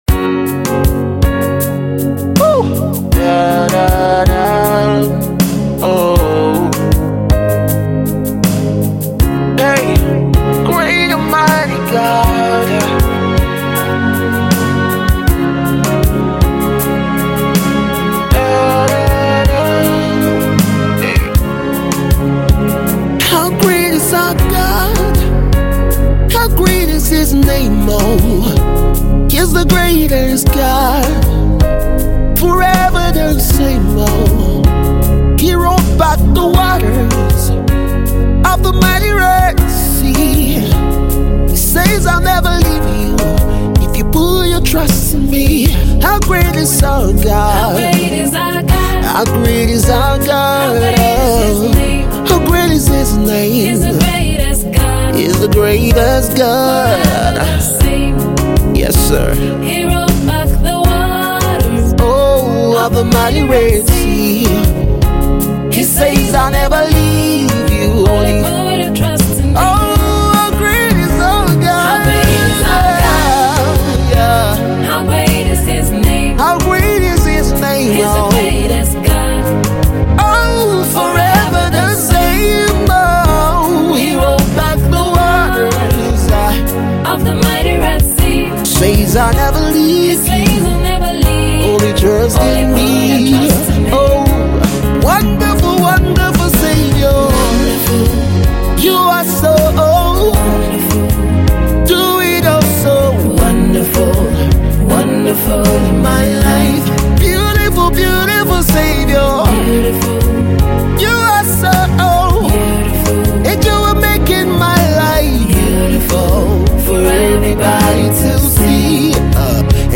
spirit-filled song